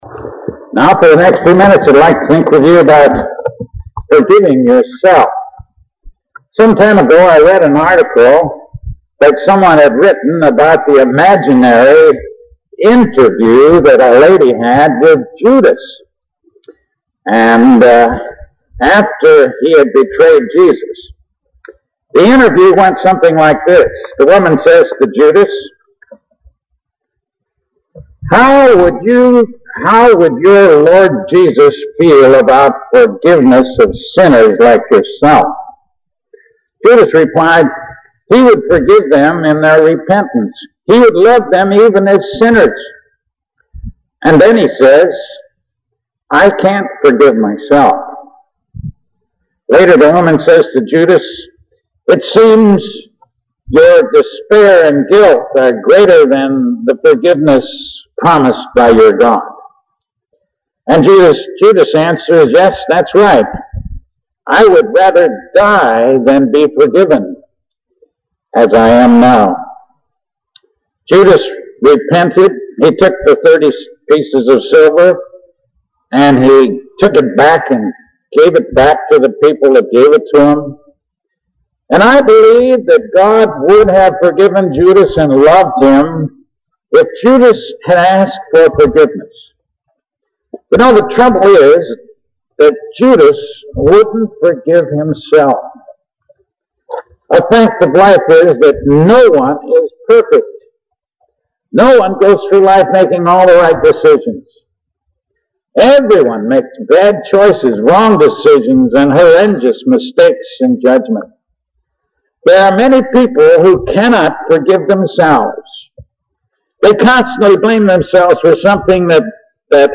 Forgiving Yourself Filed Under: All Christian Sermons , Forgiveness , God and Jesus Love You